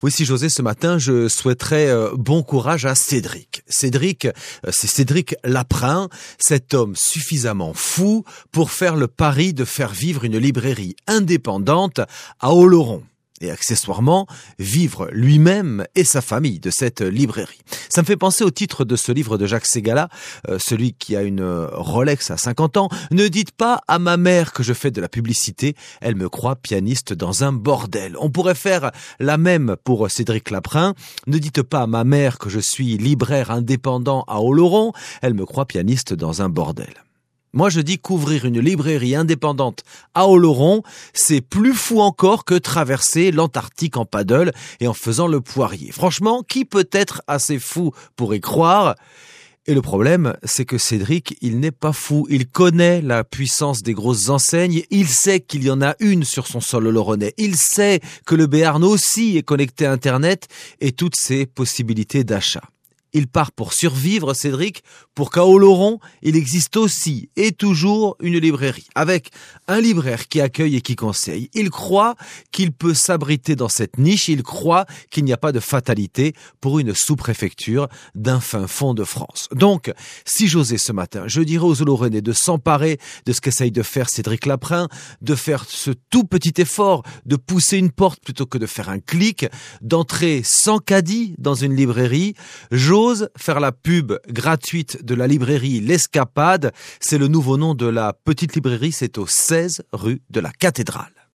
03 novembre 2016 : chronique « si j’osais » sur